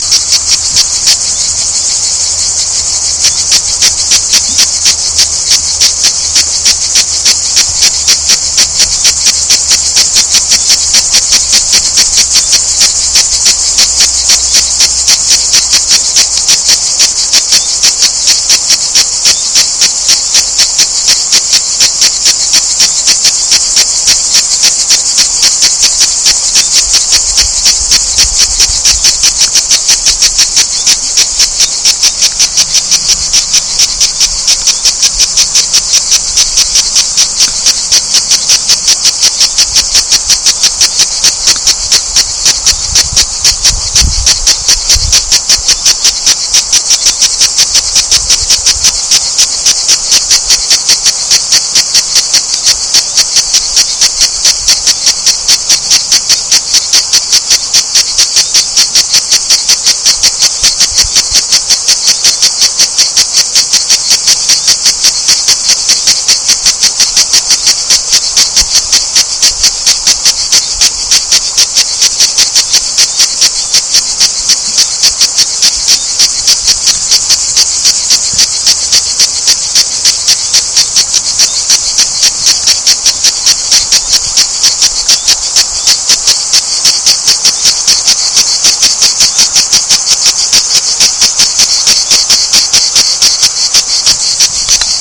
Démonstration en image : un reportage photo, pris au bord du fleuve Hérault (34) , dans l'Hérault donc, le 9 Juillet 2006.
Un lecteur MP3 équipé d'un micro positionné en mode enregistrement.
L'enregistrement est meilleur à proximité, bien sûr, ici , une cigale grise.
Le chant est un peu timide, il a du mal à démarrer. sans doute à cause de la proximité de l'appareil enregistreur ...
Conclusion : les enregistrements au moyen de ce genre d'appareil sont parfois fortement parasités (bruits d'ambiance), mais ils sont largement suffisants pour se faire confirmer une détermination sur des espèces simples, surtout accompagné d'une photographie !